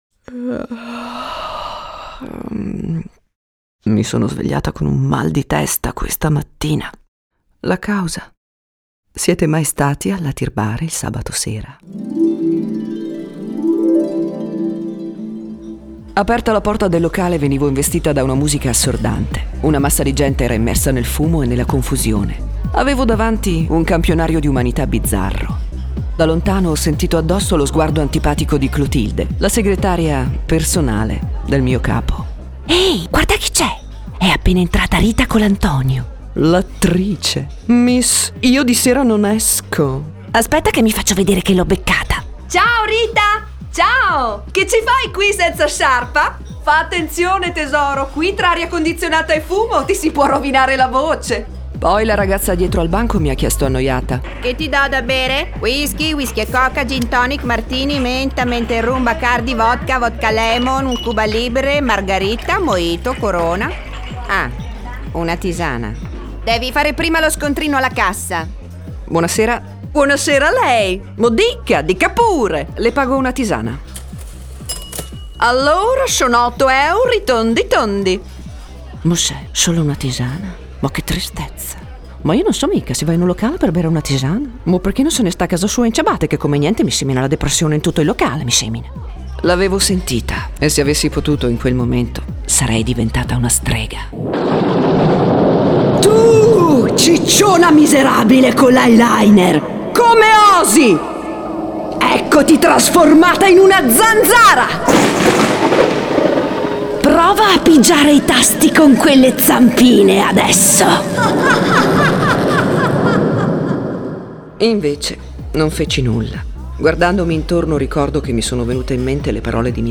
Voce in demo ambientata